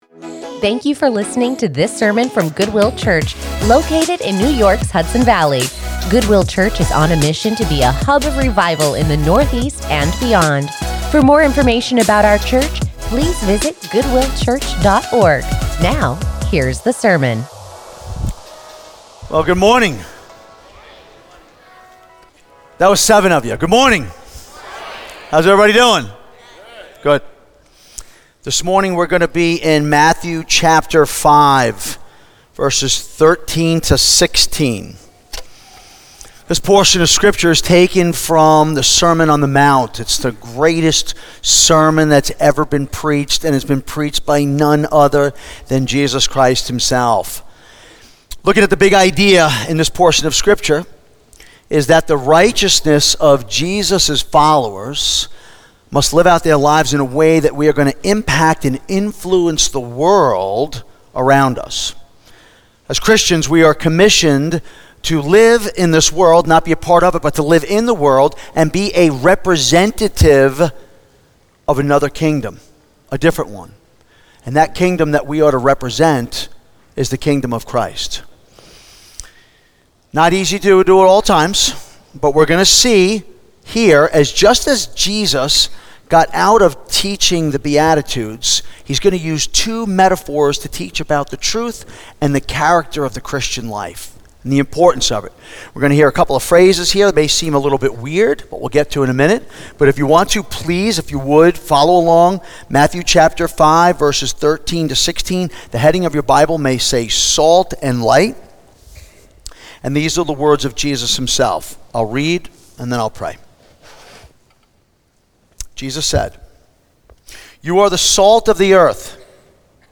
| Montgomery | Goodwill Church